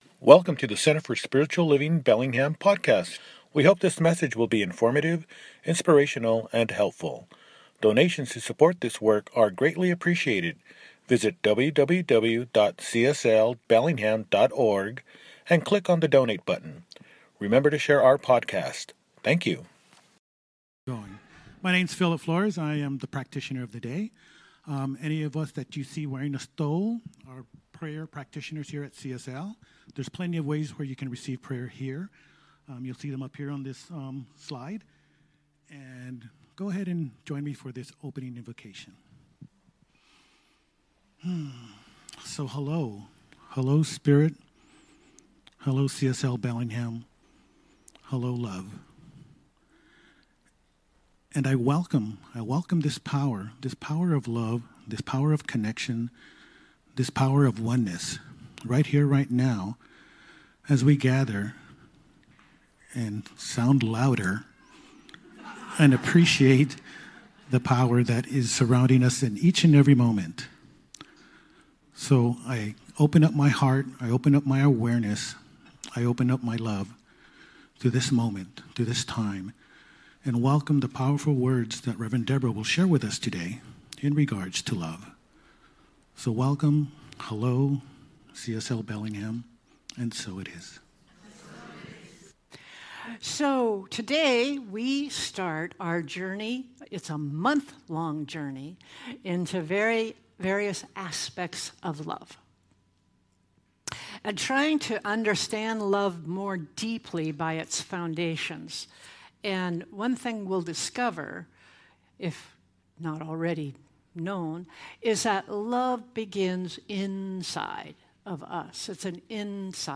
– Celebration Service